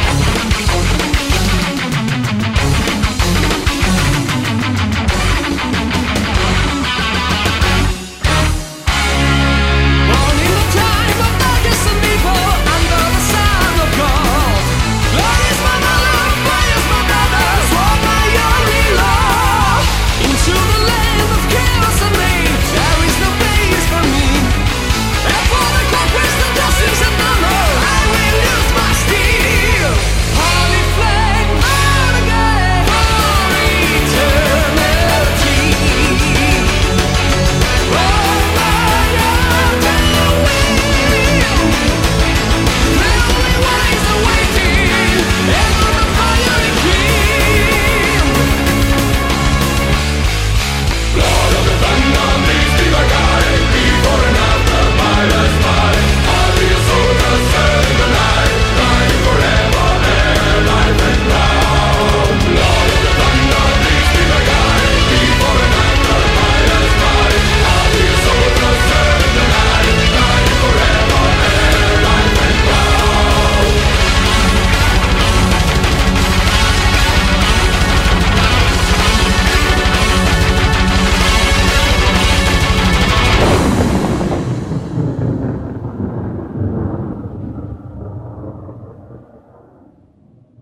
BPM190
Audio QualityPerfect (High Quality)
dynamic symphonic metal track